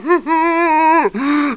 zombie_voice_idle14.mp3